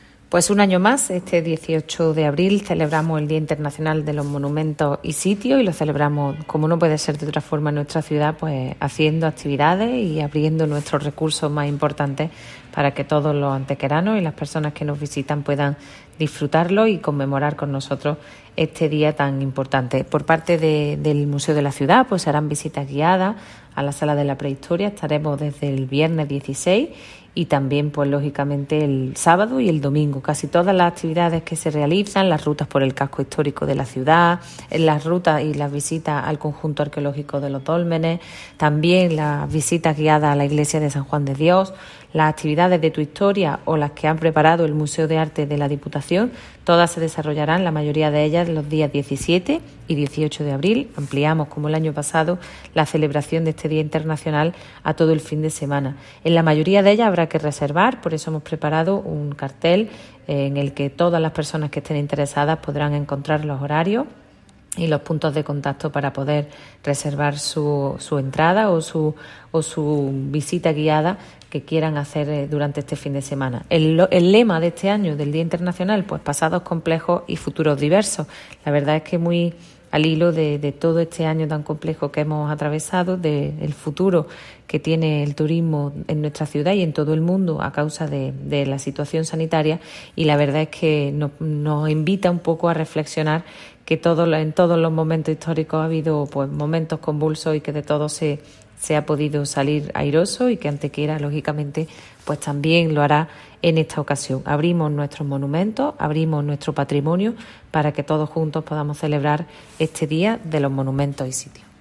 La teniente de alcalde Ana Cebrián invita a la participación en este conjunto de actividades extraordinarias que se han organizado en torno a monumentos y enclaves patrimoniales de interés, recordando la importancia de formalizar la reserva dado el caso.
Cortes de voz